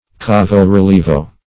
Cavo-relievo \Ca"vo-re*lie"vo\, n.